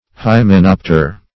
Hymenopter \Hy`me*nop"ter\, n. [Cf. F. hym['e]nopt[`e]re.]